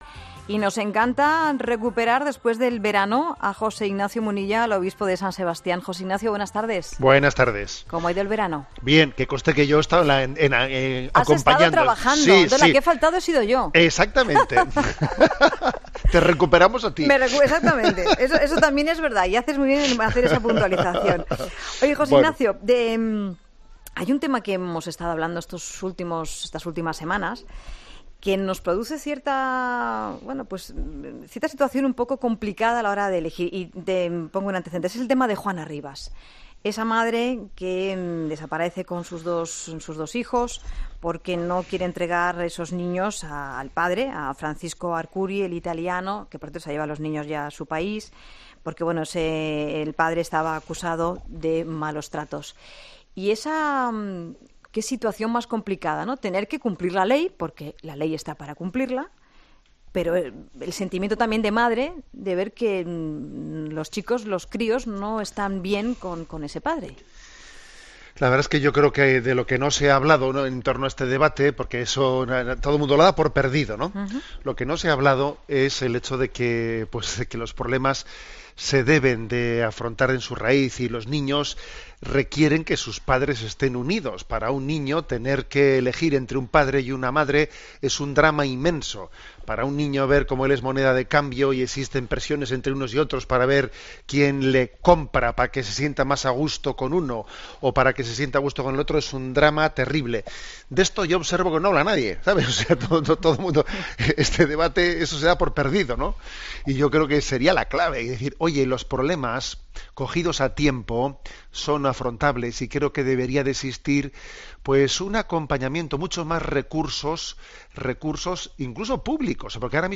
ESCUCHA LA ENTREVISTA COMPLETA | José Ignacio Munilla, obispo de San Sebastián José Ignacio Munilla